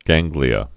(găngglē-ə)